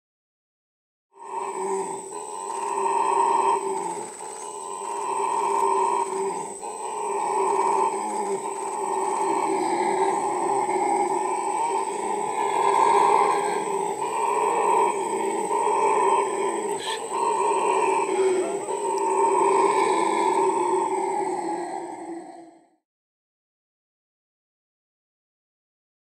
Singe-hurleur.mp3